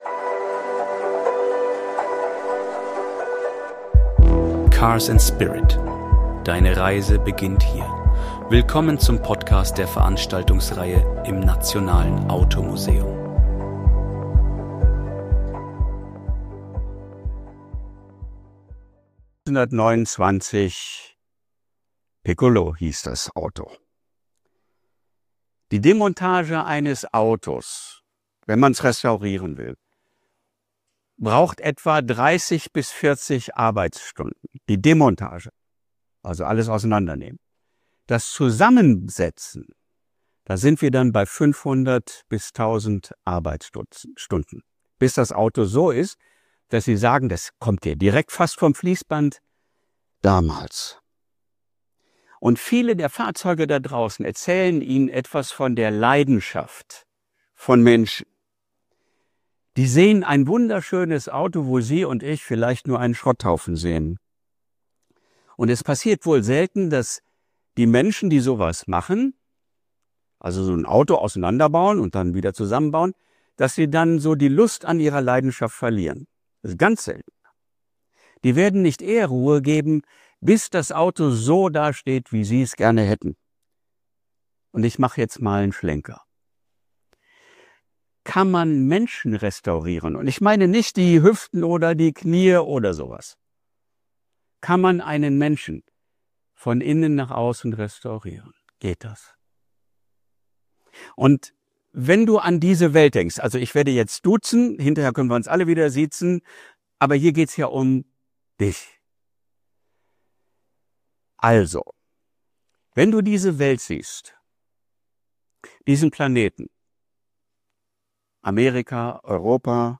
Beschreibung vor 9 Monaten Im Nationalen Automuseum beginnt alles mit einem kleinen Auto namens Piccolo – doch schnell wird klar: Dieser Vortrag dreht sich um viel mehr als nur Technik.